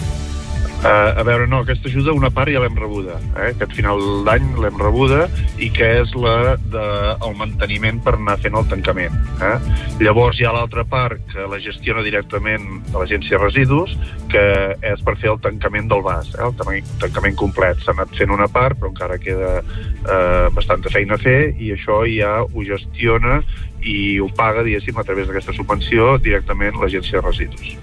Entrevistes Supermatí
I per parlar de l’actualitat d’aquest procés de tancament i dels propers mesos del complex ens ha visitat al Supermatí el president del Consorci de Solius i alcalde de Llagostera, Narcís Llinàs.